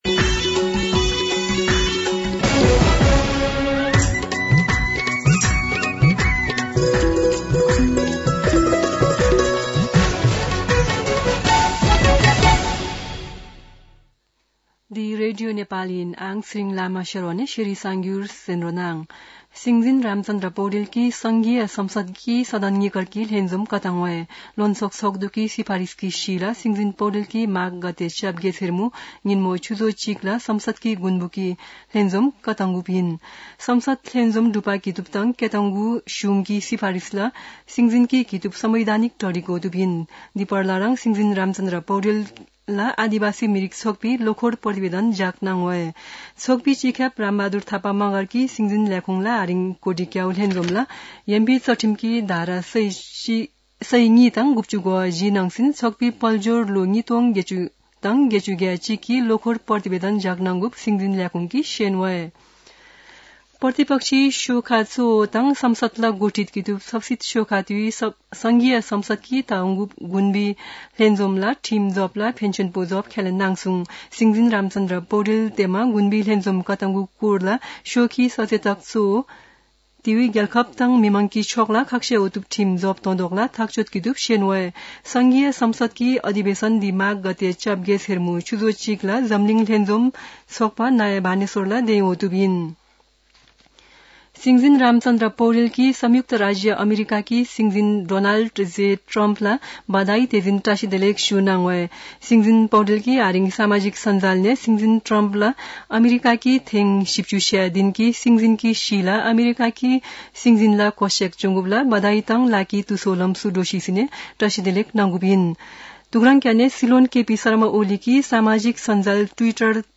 शेर्पा भाषाको समाचार : ९ माघ , २०८१
Sherpa-News-10-8.mp3